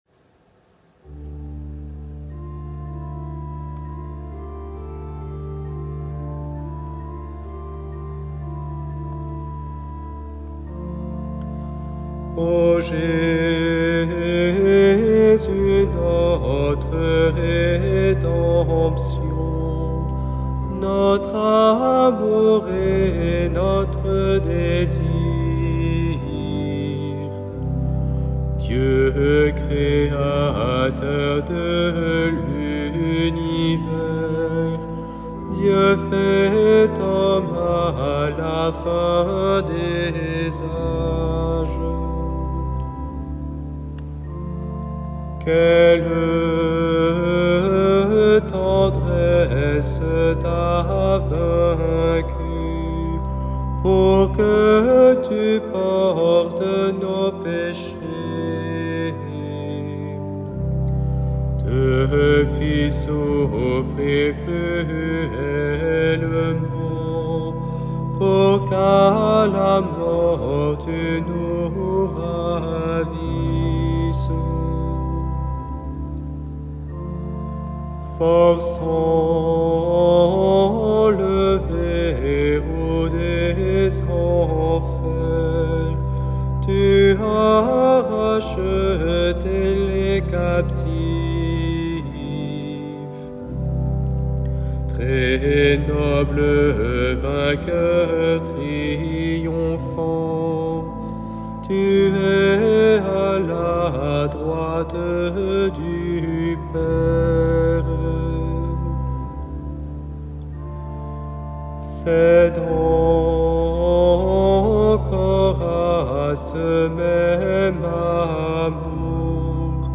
vepres-temps-pascal-le-jour-de-lascension-francais.m4a